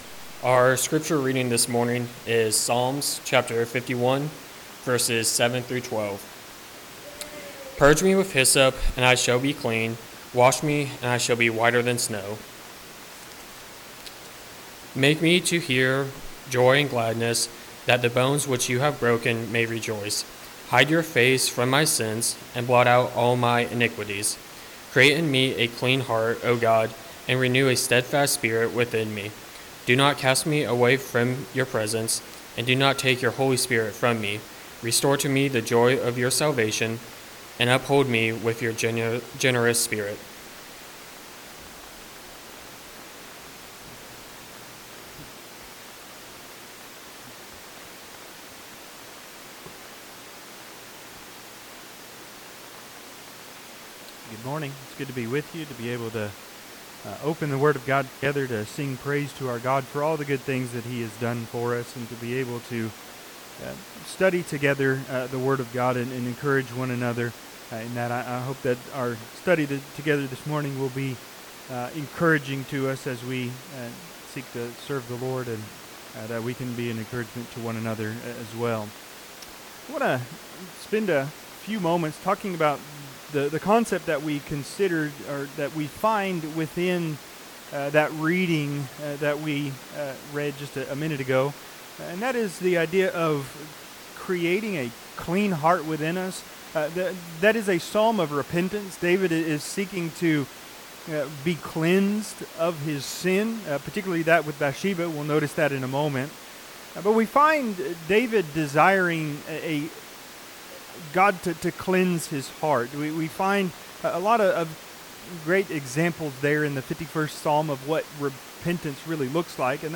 Ps. 51:7-12 Service Type: Sunday AM « What Is The Greatest Commandment?